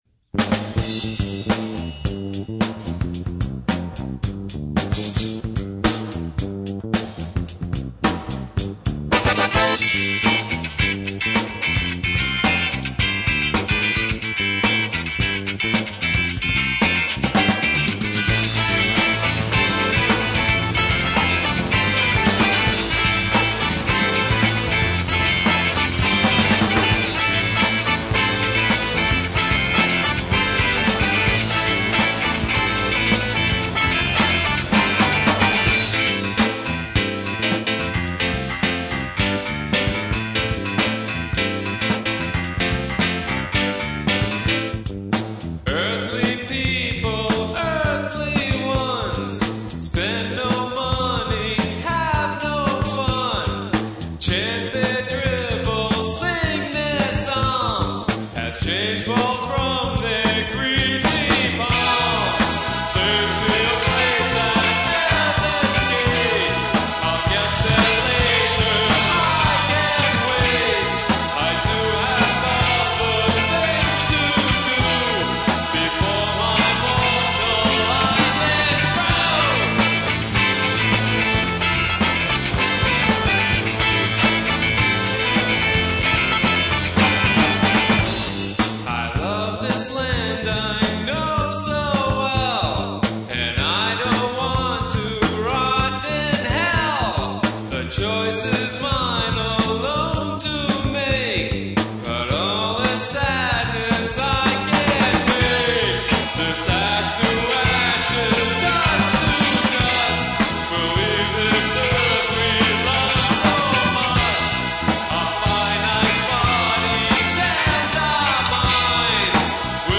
Keyboards, Vocals
Drums, Vocals
Bass, Vocals
Guitar, Lead Vocals